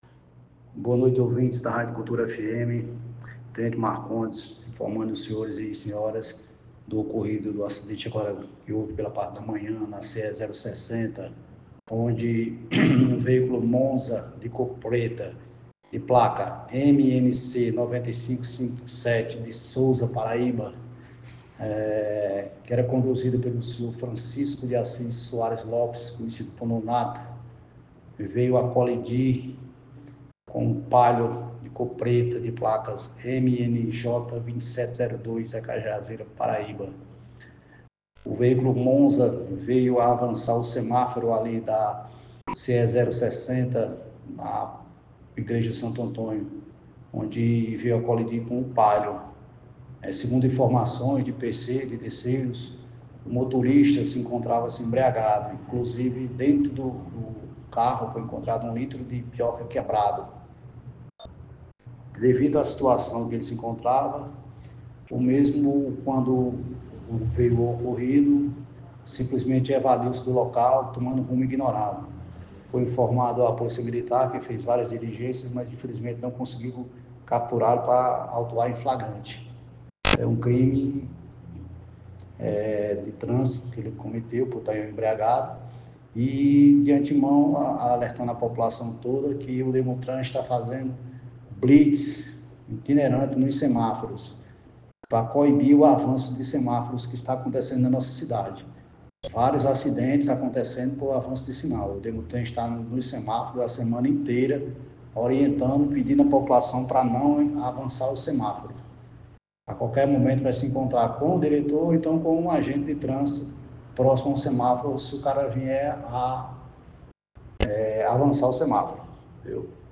A informação é do diretor do Departamento Municipal de Trânsito – Demutran, Tenente Marcondes, no jornal Giro 360 (18h às 19h), na Rádio Cultura (FM 96.3).